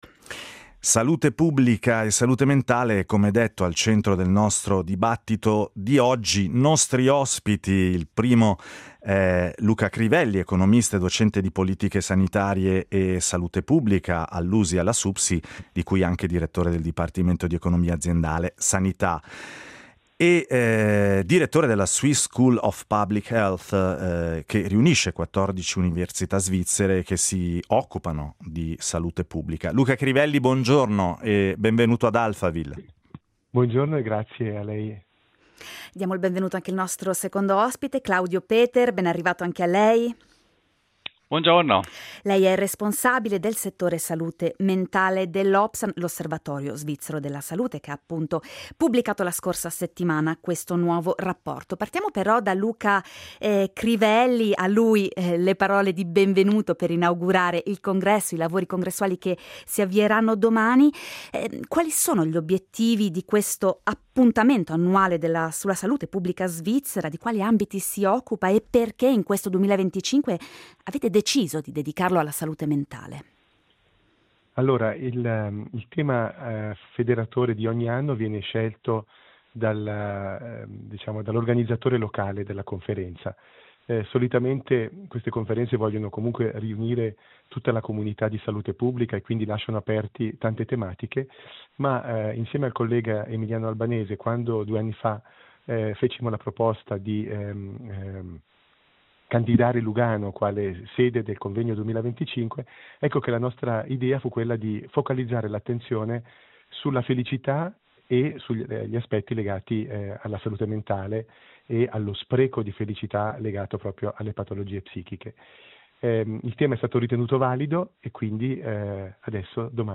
Ad Alphaville abbiamo approfondito il tema con due ospiti d’eccezione